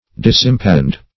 Disimpassioned \Dis`im*pas"sioned\, a.